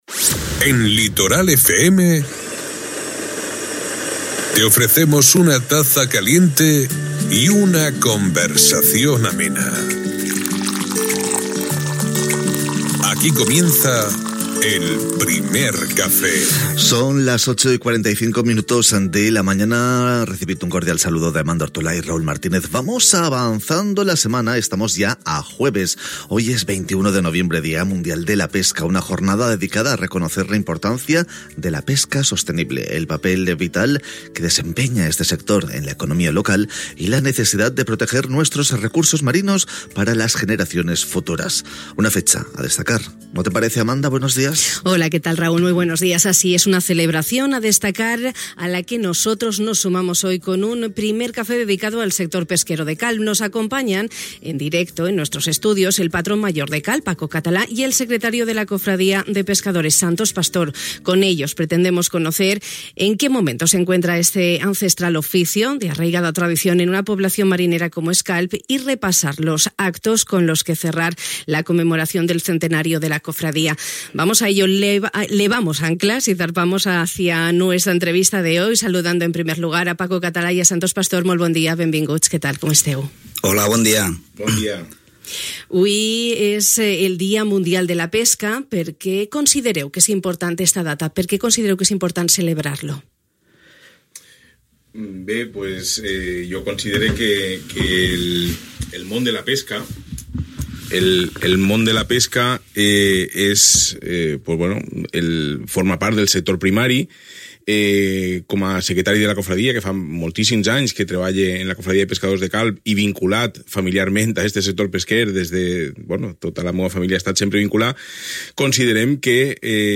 Una celebració a la qual nosaltres, des de Radio Litoral, ens hem sumat amb un Primer Café dedicat al sector pesquer de Calp. Ens han acompanyat en directe als nostres estudis